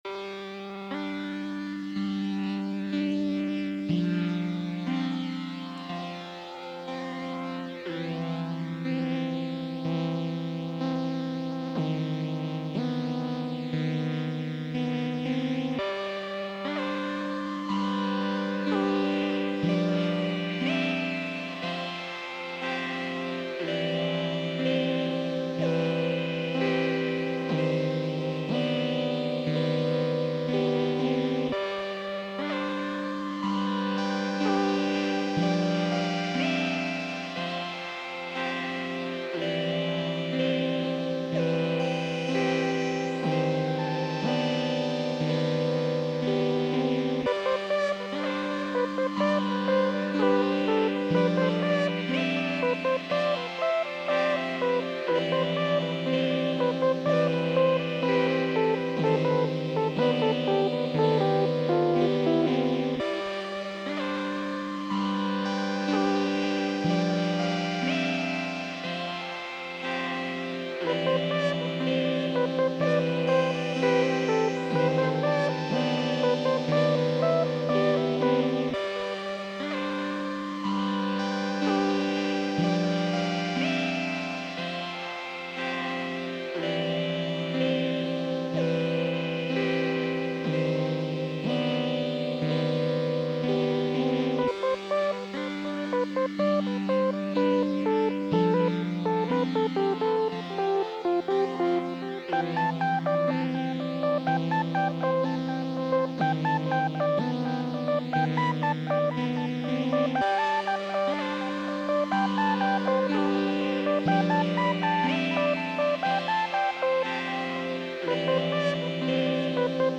– Arturia Microfreak, Ableton Live, June 2024